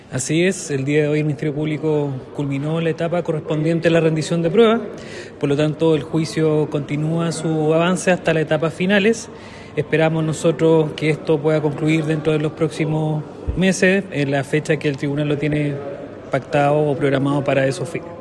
El fiscal del Ministerio Público de Coyhaique, Jaime Rojas, quien integra el equipo que sostiene la acusación en esta causa, confirmó a La Radio, el término de la presentación de su prueba.